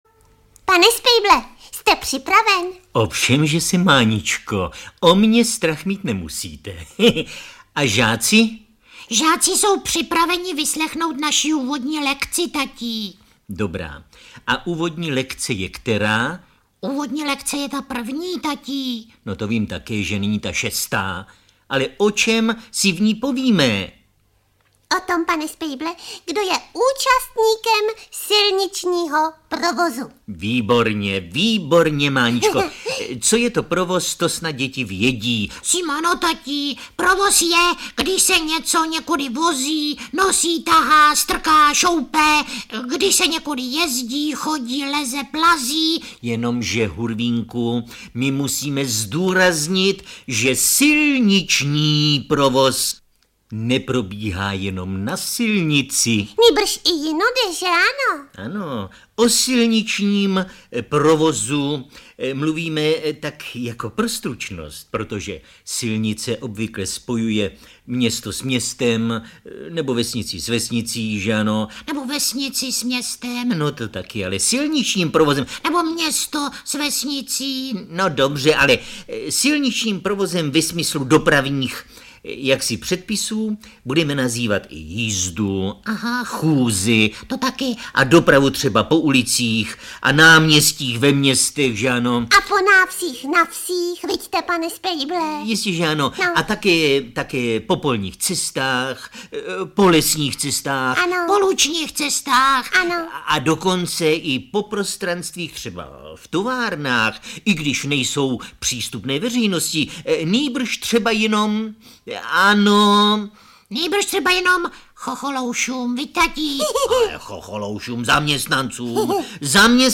Rozhlasová nahrávka z roku 1981
Účinkují Miloš Kirschner a Helena Štáchová